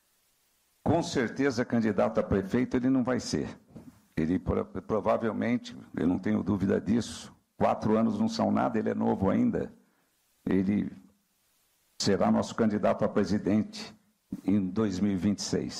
O presidente nacional do PL, Valdemar da Costa Neto, anunciou durante entrevista coletiva que o atual presidente Jair Bolsonaro (PL) será o candidato da legenda na corrida presidencial de 2026.
Os comentários do político foram registrados pelo programa Correio Debate, da 98 FM, de João Pessoa, nesta quarta-feira (09/11).